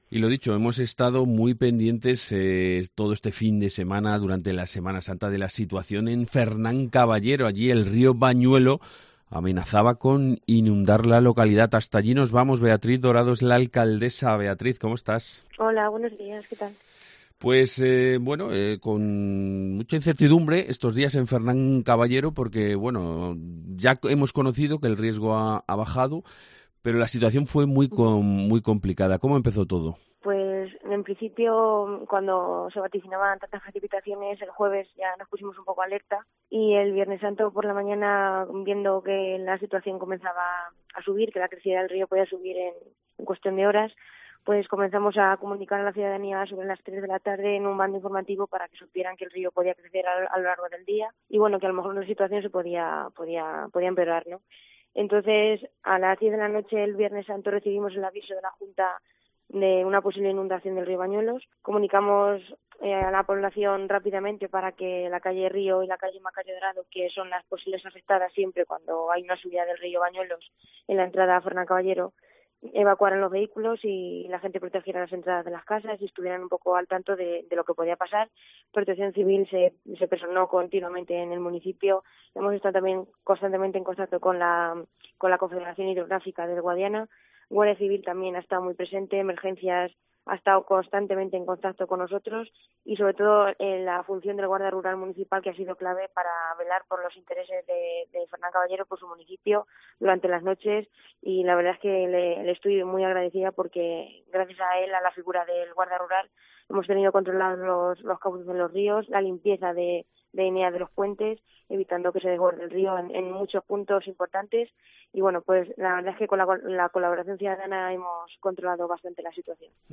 Entrevista con la alcaldesa de Fernán Caballero, Beatriz Dorado